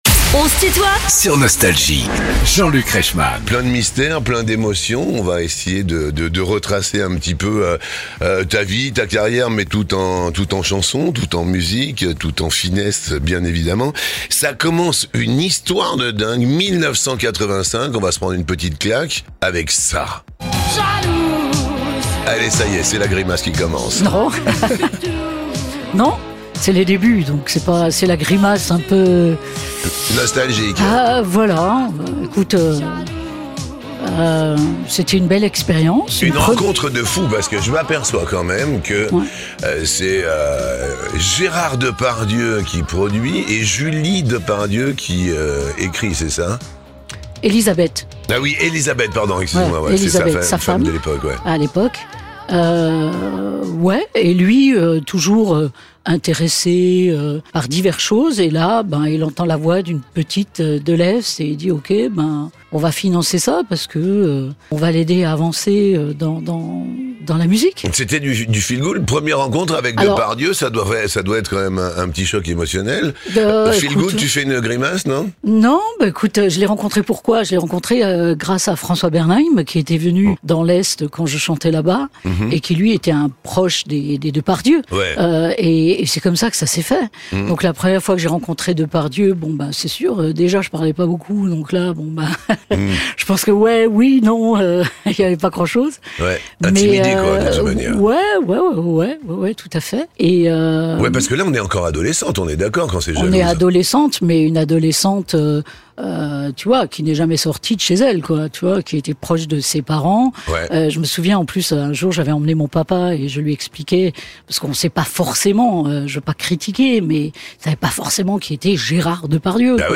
Patricia Kaas est l'invitée de "On se tutoie ?..." avec Jean-Luc Reichmann